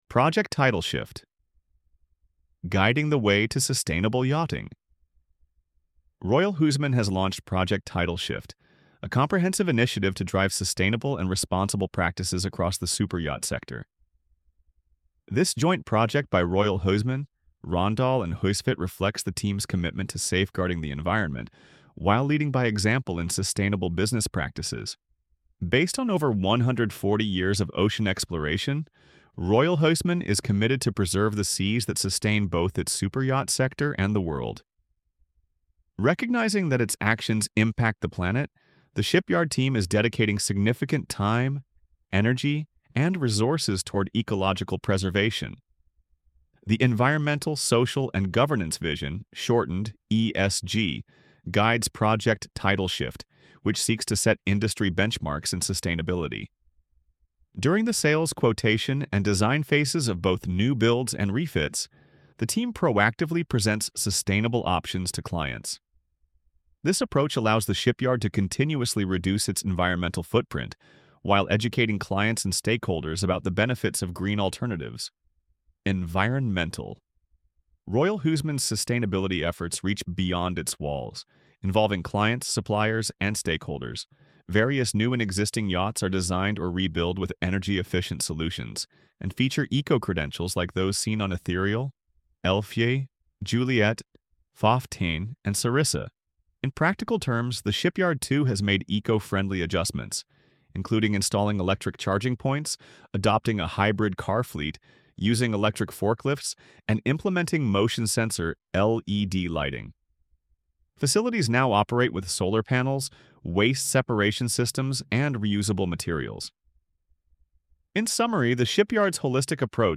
Listen to audio text produced by Elevenlabs ai: